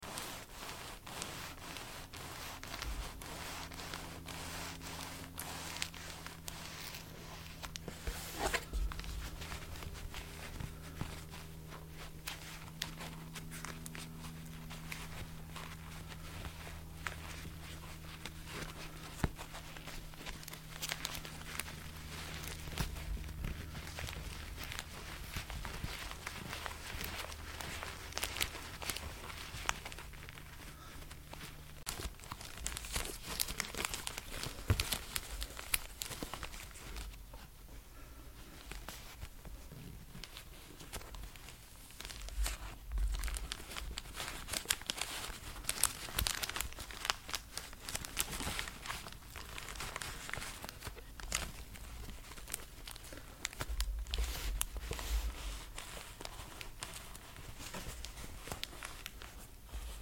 Quick Tissue Box Tapping to sound effects free download